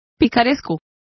Complete with pronunciation of the translation of roguish.